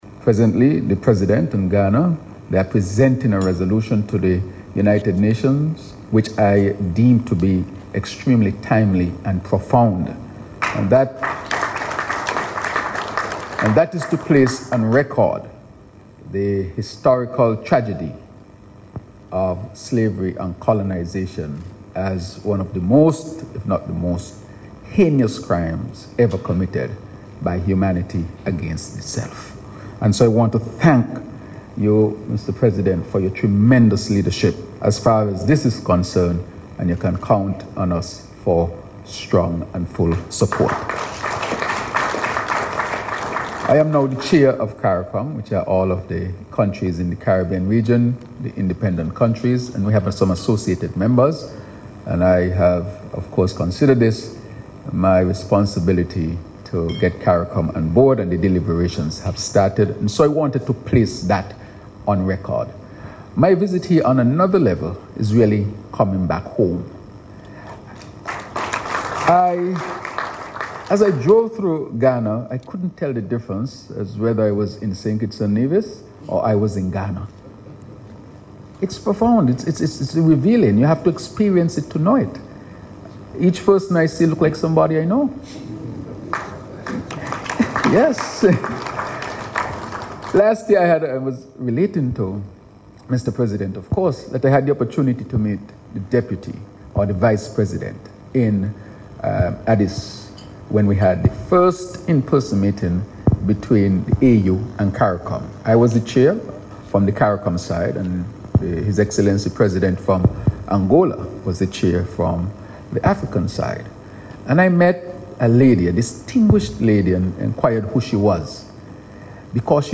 LISTEN TO DR. TERRANCE DREW IN THE AUDIO BELOW:
The luncheon underscored a renewed commitment by Ghana and its Caribbean partners to convert historical bonds into concrete economic, political, and cultural collaboration for future generations.